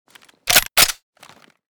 famas_unjam.ogg